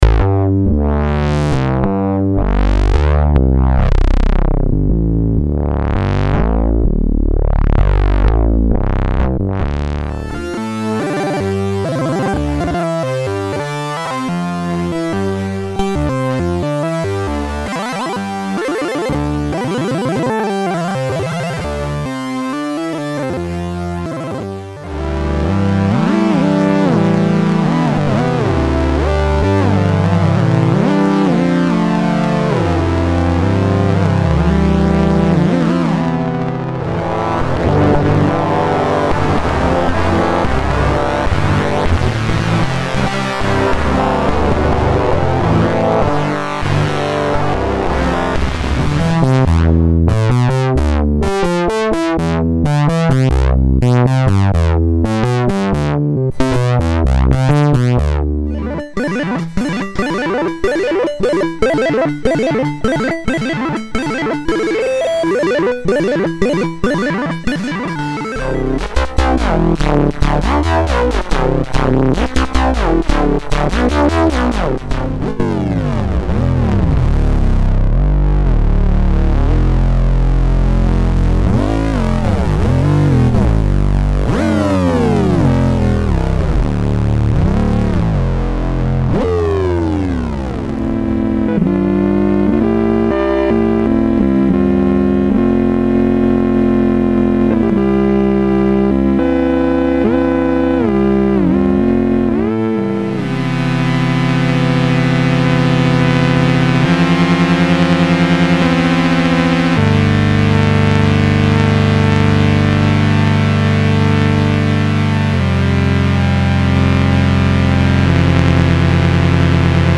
Commodore 64 musical instrument with Cynthcart software.
[ sound demo]
cynthcart_demo.mp3